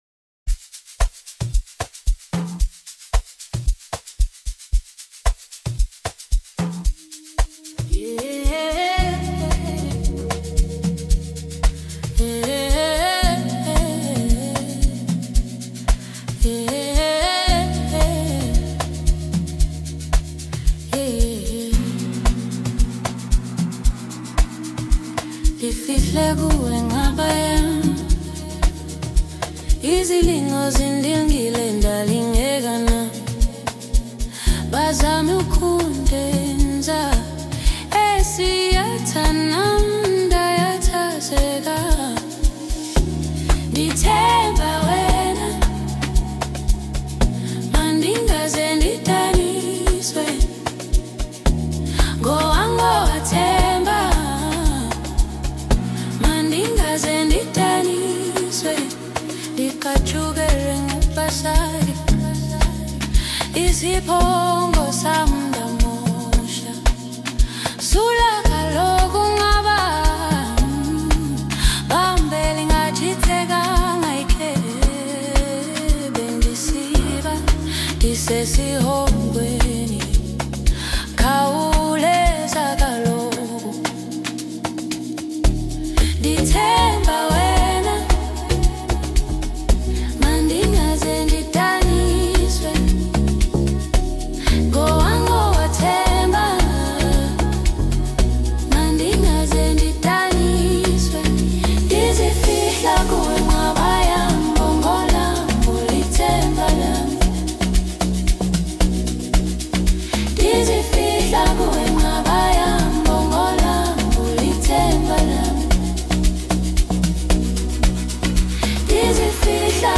• Artist: New Amapiano Songs 2026